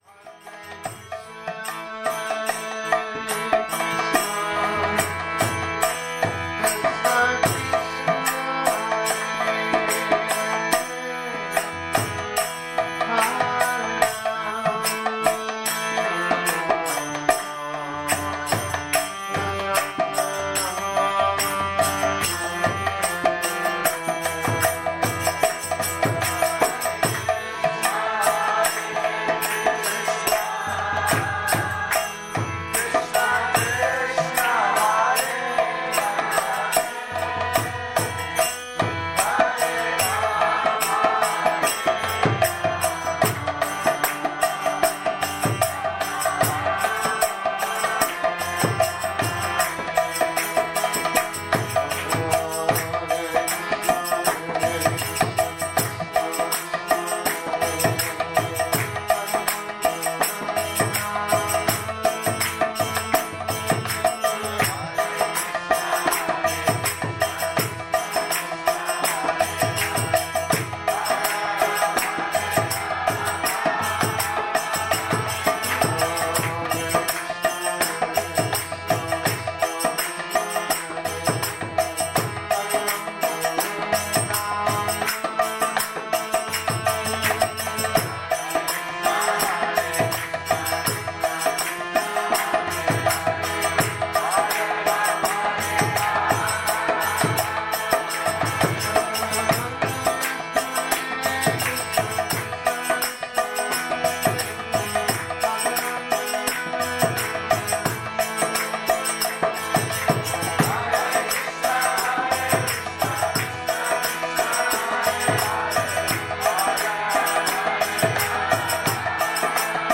Kírtan Góvinda